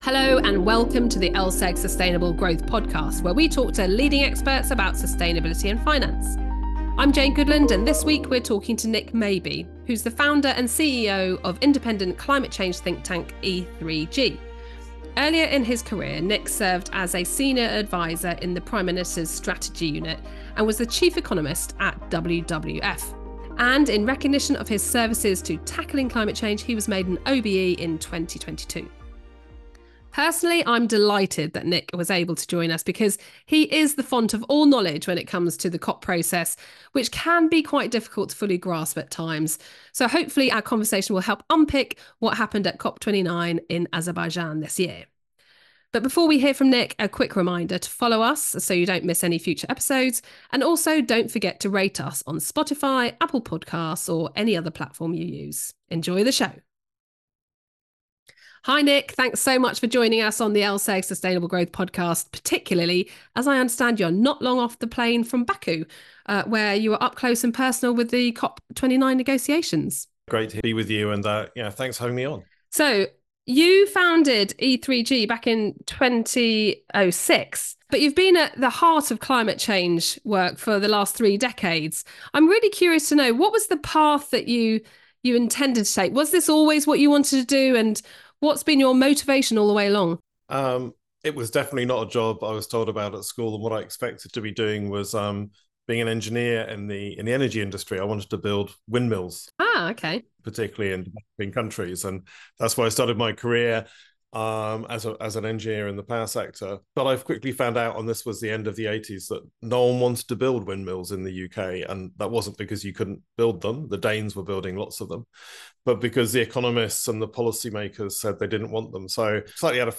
Numbers 10 41:26 Play Pause 2d ago 41:26 Play Pause Nghe Sau Nghe Sau Danh sách Thích Đã thích 41:26 LS Community Church live Sunday gathering, November 24